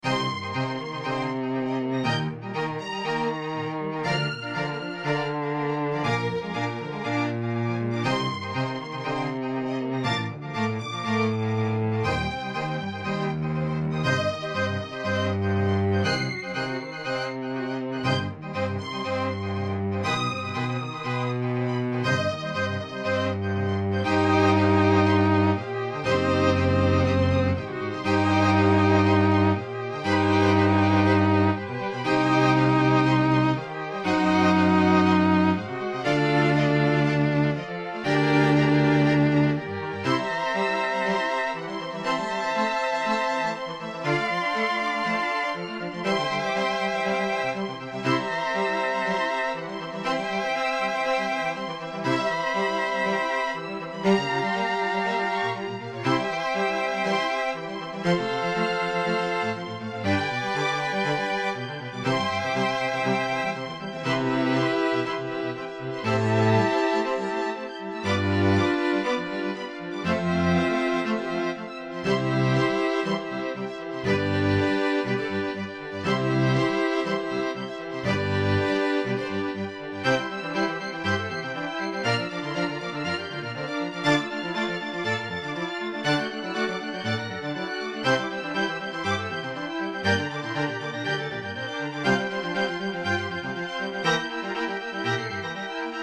トレモロ ストリングス、バイオリン、ビオラ、チェロ
BGM